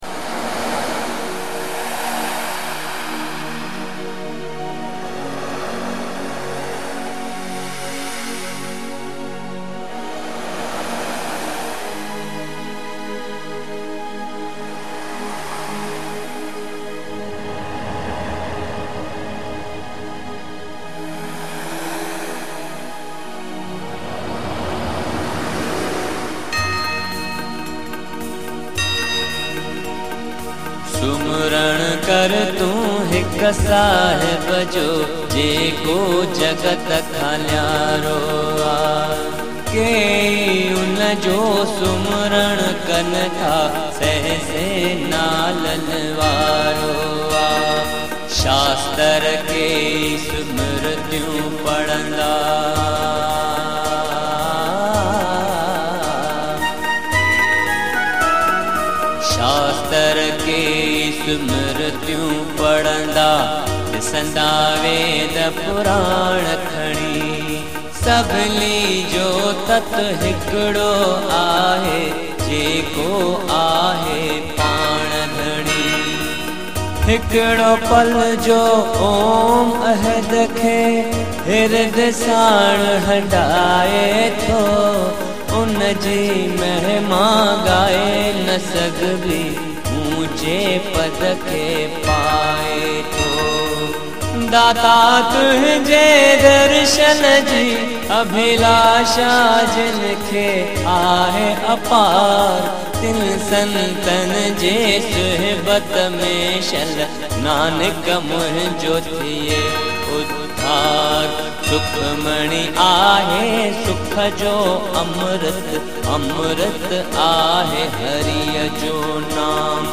An Authentic Ever Green Sindhi Song Collection